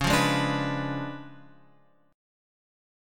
C#7b9 chord {9 8 6 7 x 7} chord